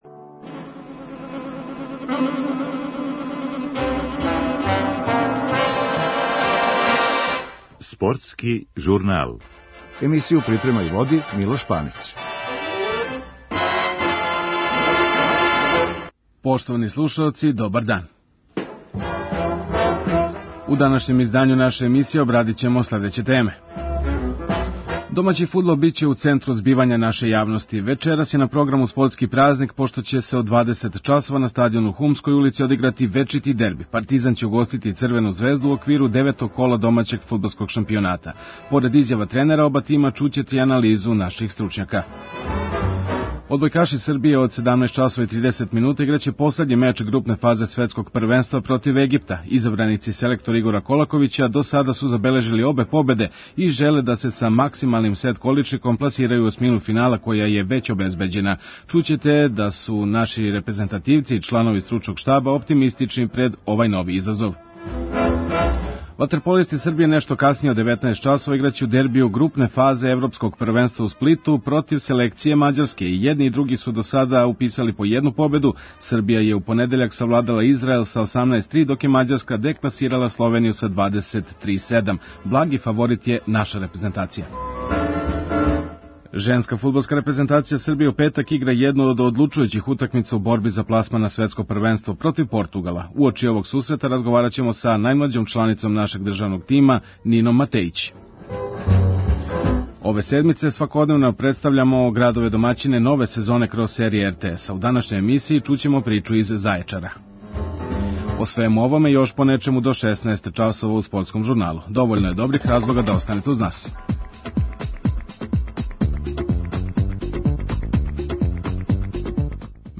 Поред изјава тренера оба тима, чућете и анализу наших стручњака.